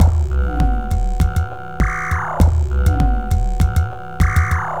Downtempo 03.wav